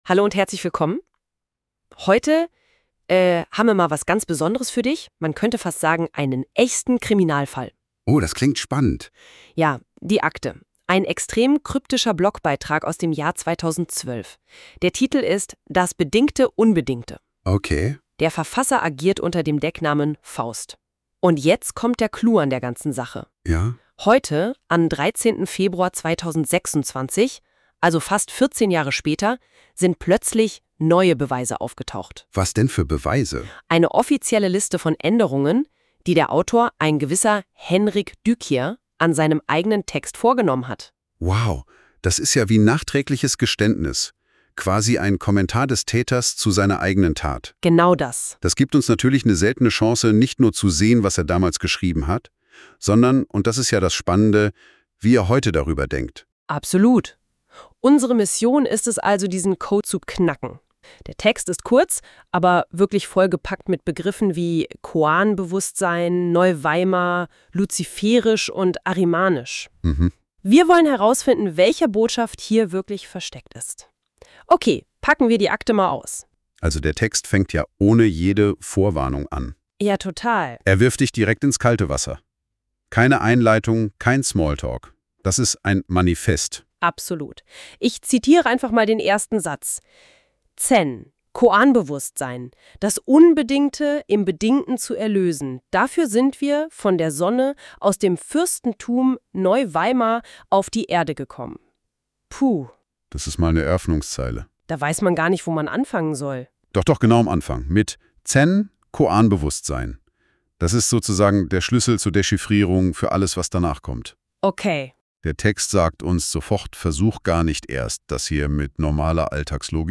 Podcast des poetologischen Fragmentes im Stil einer Rezension The Conditional Unconditional: Aesthetic Autonomy and Combatant Love